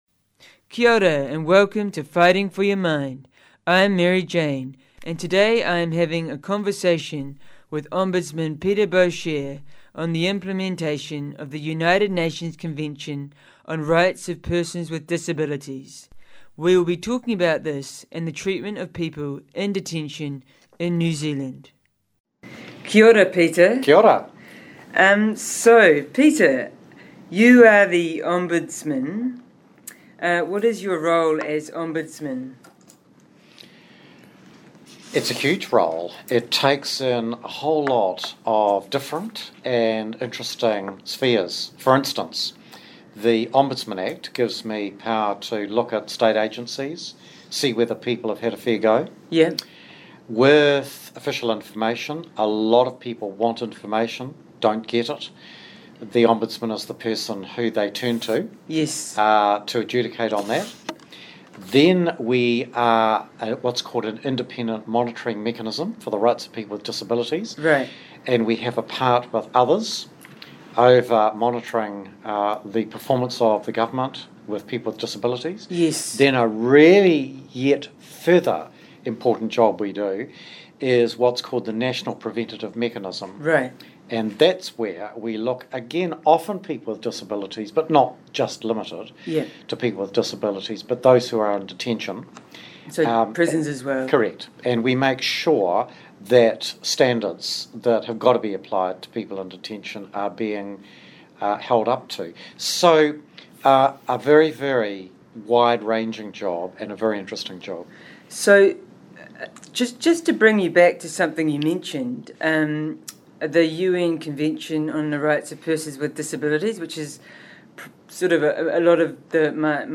Conversation with New Zealand Ombudsman Peter Boshier